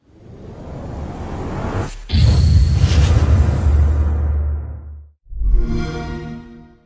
Play, download and share xbox series startup original sound button!!!!
xbox-series-startup.mp3